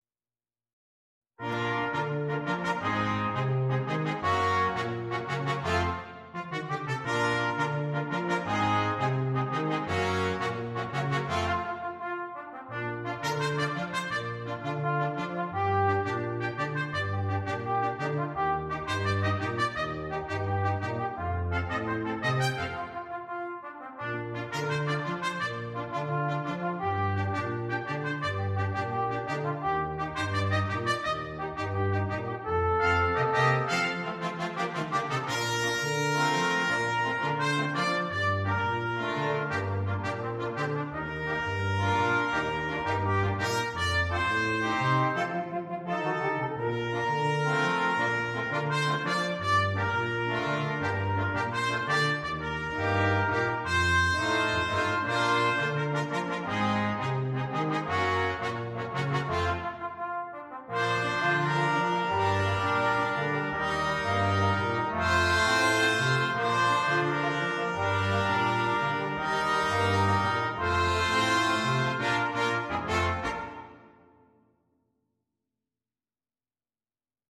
для брасс-квинтета